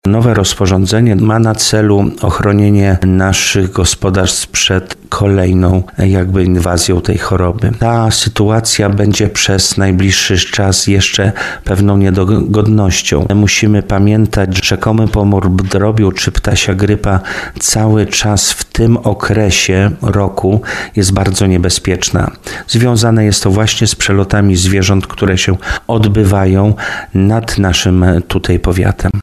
Powiatowy Lekarz Weterynarii w Tarnowie Paweł Wałaszek podkreśla jak ważne jest przestrzeganie zaleceń w celu ochrony hodowli drobiu.